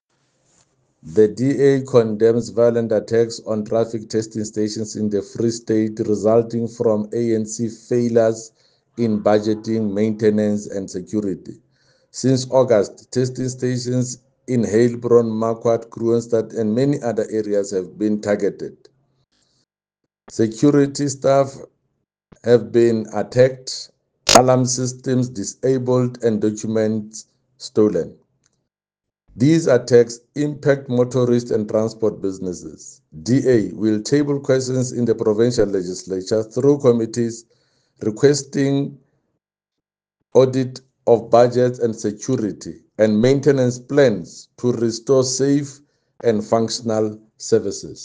Sesotho soundbites by Jafta Mokoena MPL